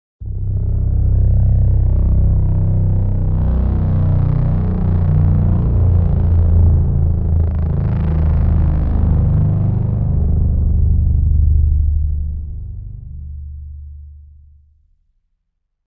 Powerful Large Drone Hover Sound Effect
A deep, resonant drone hover sound with a powerful and immersive tone. Perfect for sci-fi scenes, futuristic technology, films, games, and multimedia projects needing a large, hovering mechanical or aerial presence.
Powerful-large-drone-hover-sound-effect.mp3